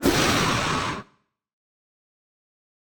guardian_hit1.ogg